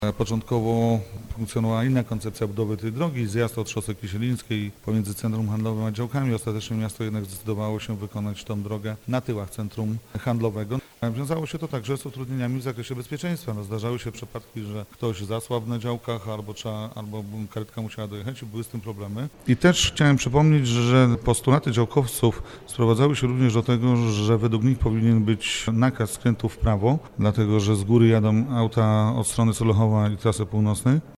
Radny z os. Pomorskiego Jacek Budziński podkreśla, że do zakończenia prac pozostało jeszcze oświetlenie skrzyżowania: